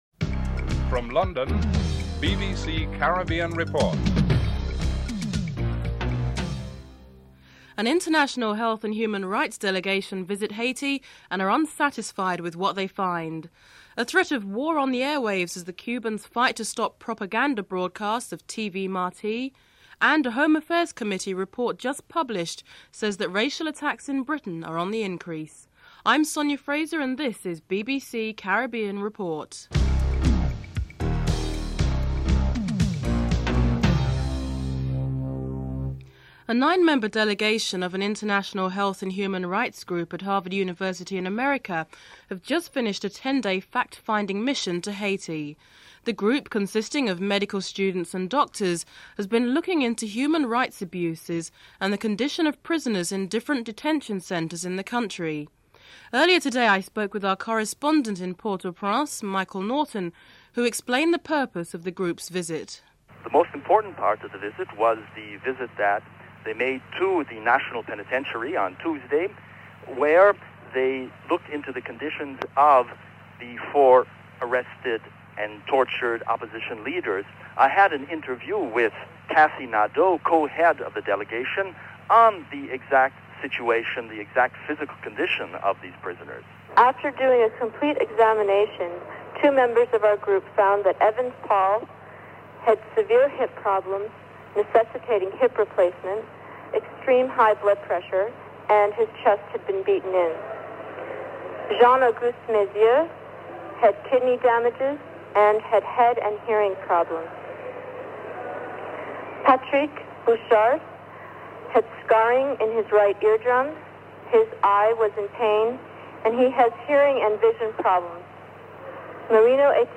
The British Broadcasting Corporation
1. Headlines (00:00 - 00:35)